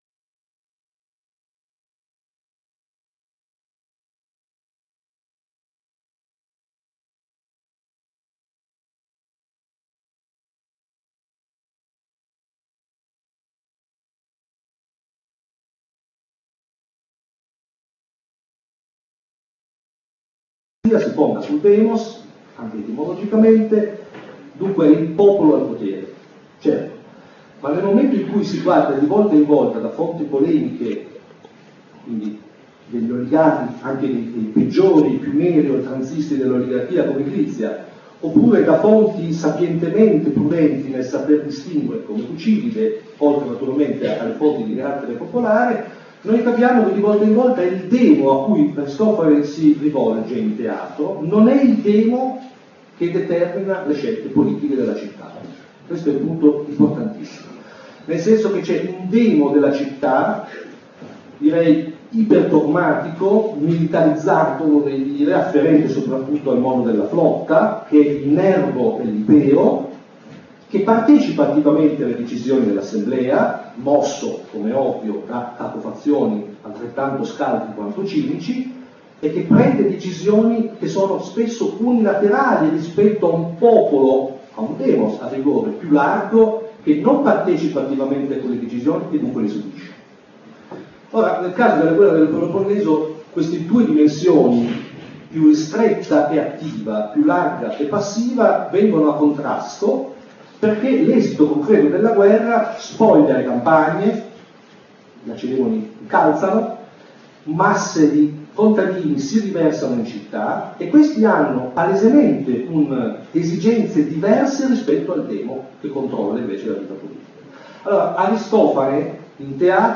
Molto numeroso il pubblico presente in sala